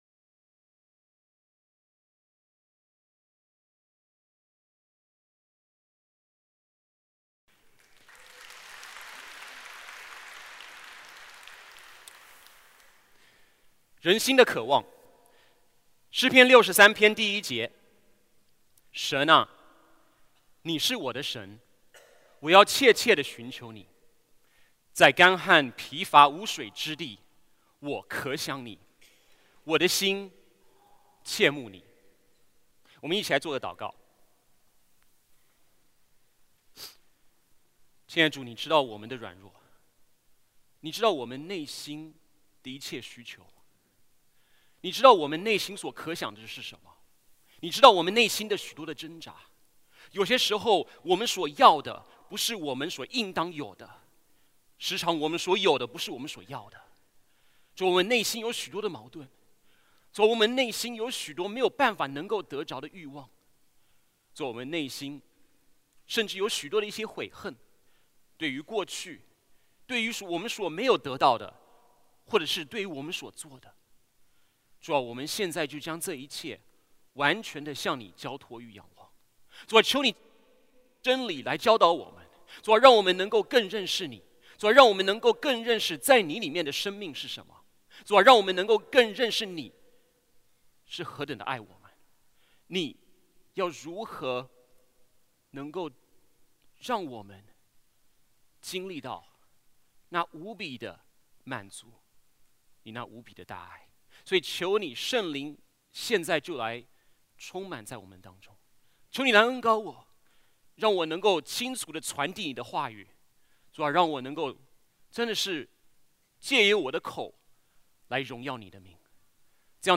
主日证道 |  人心的渴望